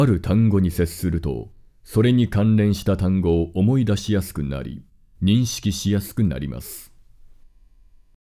音声の方も聞き比べるとチャ、パッというリップノイズが低減されているのが分かります（ノイズ注意）
修復後